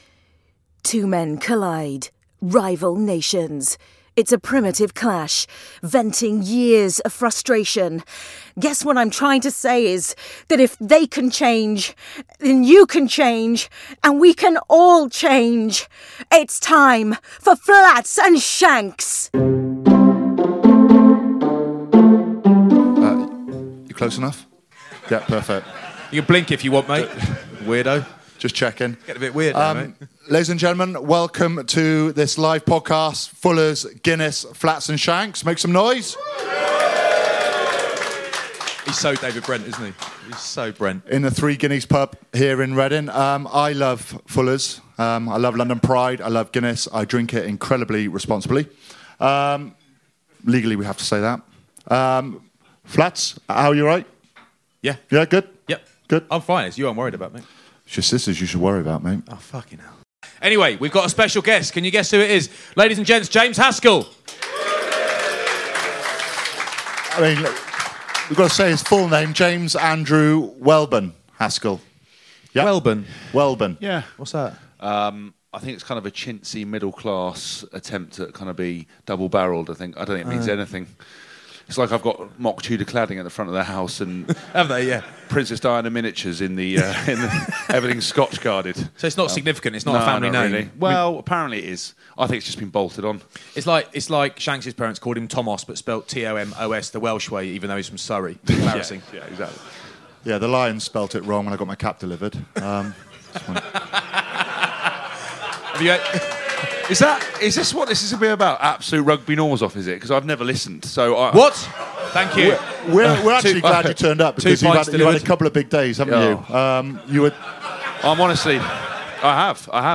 Fullers and Guinness live podcast with James Haskell